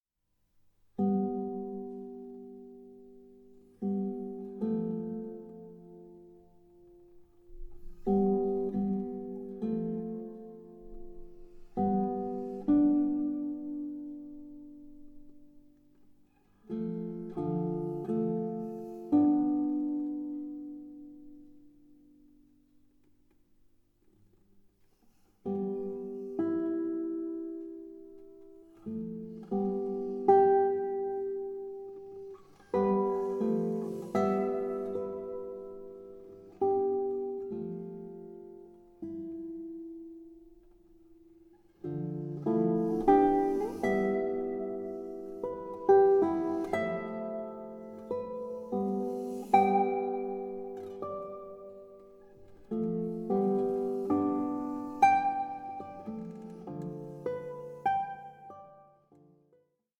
for guitar